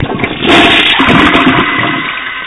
flush